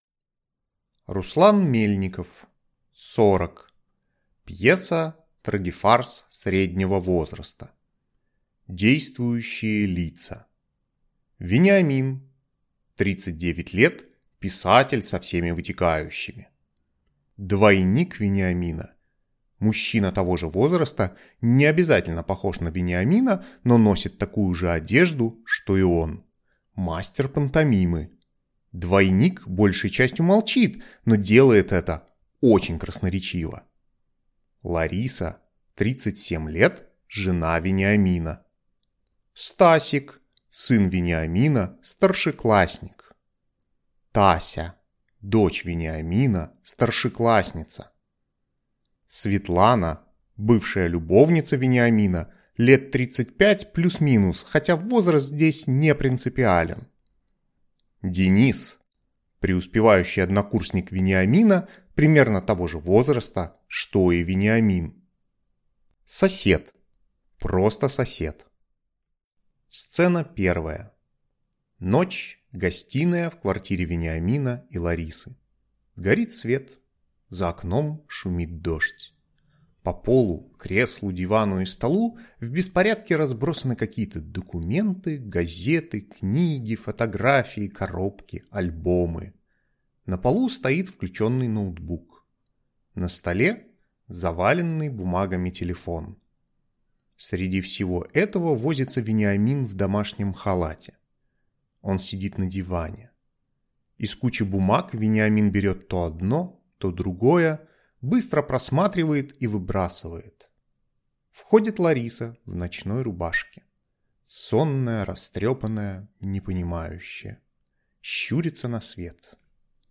Аудиокнига 40 | Библиотека аудиокниг